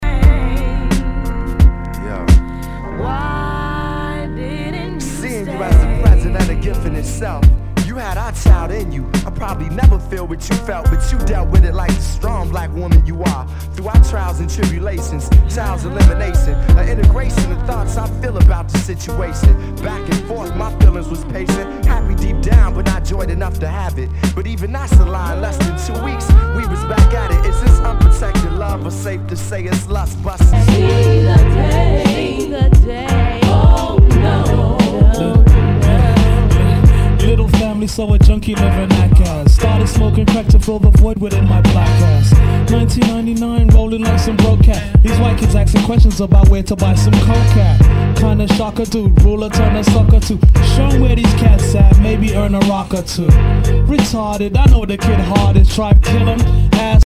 HIPHOP/R&B